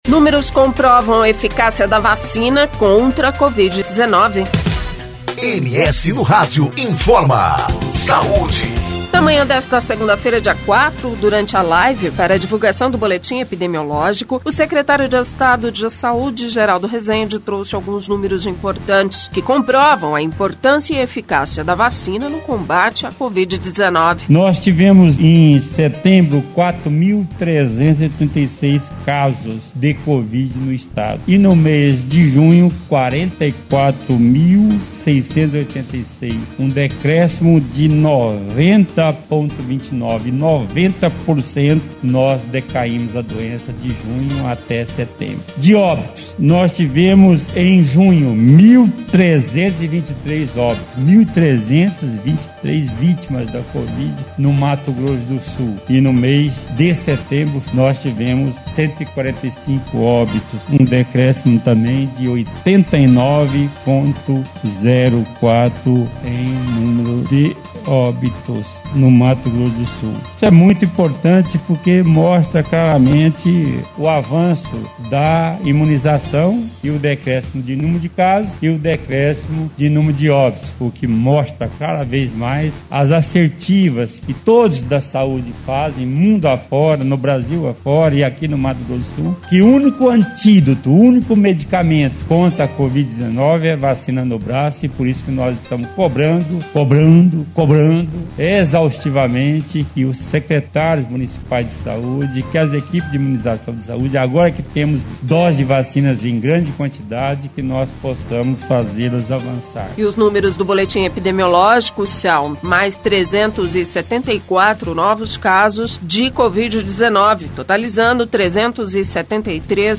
Na manhã desta segunda-feira, dia 04, durante a live para divulgação do boletim epidemiológico, secretário de estado de saúde, Geraldo Resende, trouxe alguns números importantes, que comprovam a importância e a eficácia da vacina no combate à Covid-19.